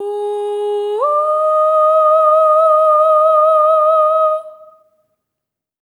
SOP5TH G#4-L.wav